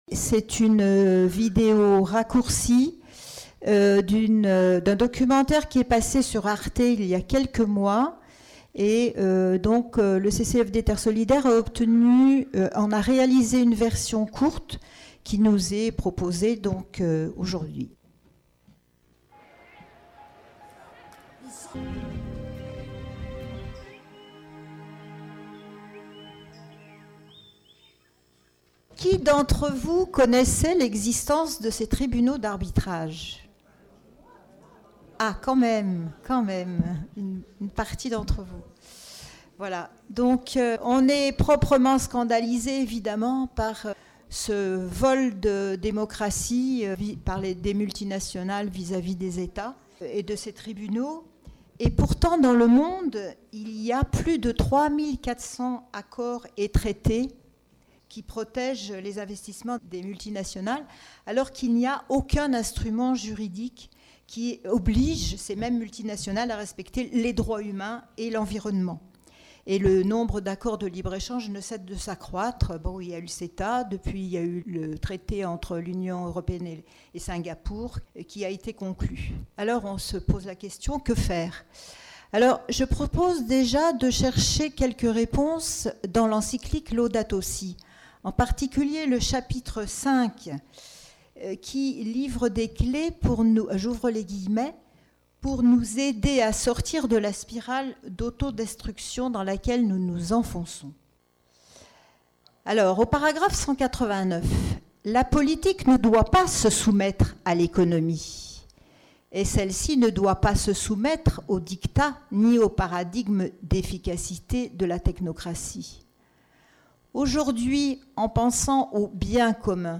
Soirée 'Europe' du 6 mai au Centre diocésain - commentaires multinationales